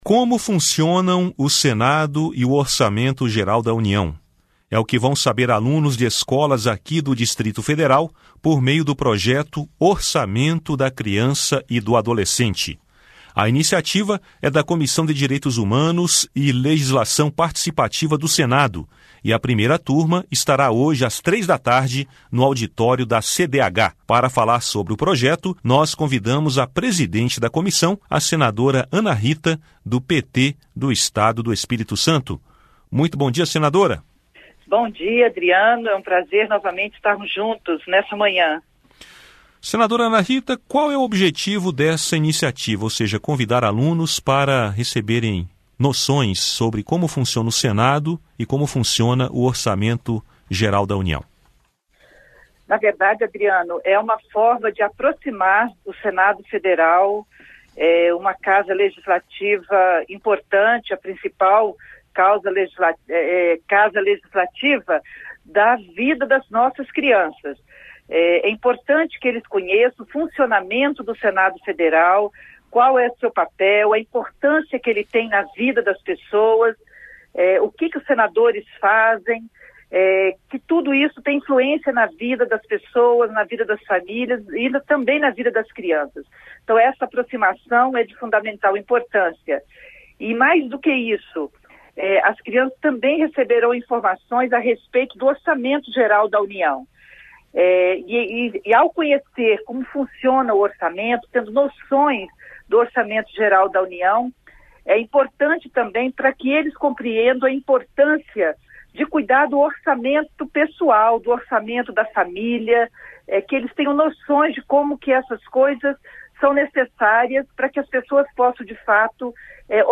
Entrevista com a presidente da Comissão de Direitos Humanos, a senadora Ana Rita (PT-ES).